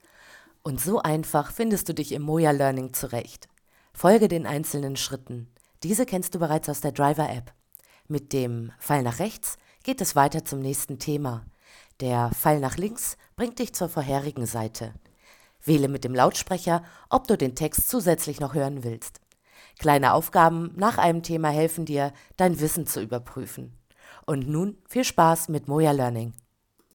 Vertonung E-learning Modul